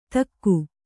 ♪ takku